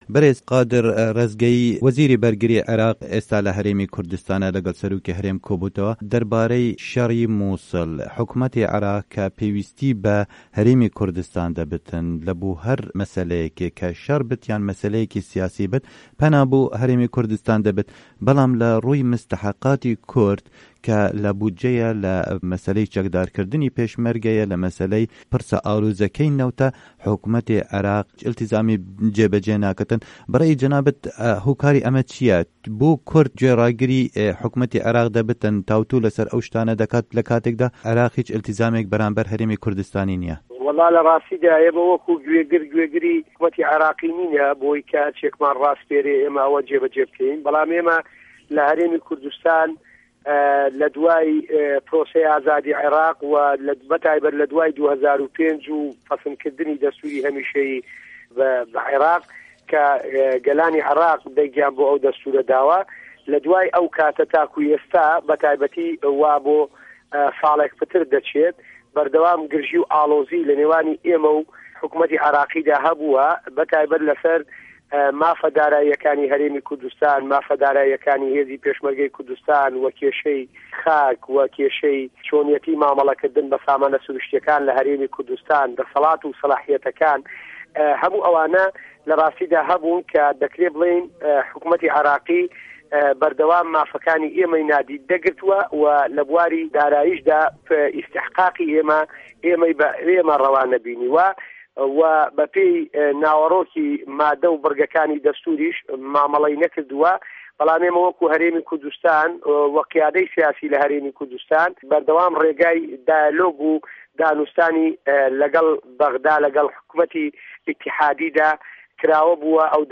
له‌ گفتوگۆ